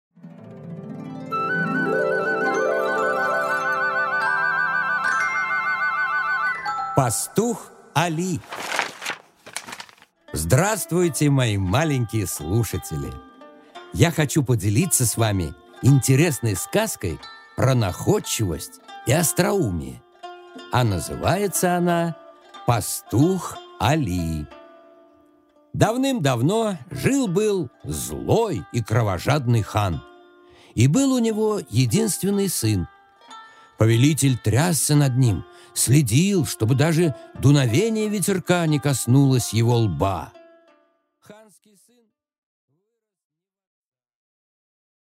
Аудиокнига Пастух Али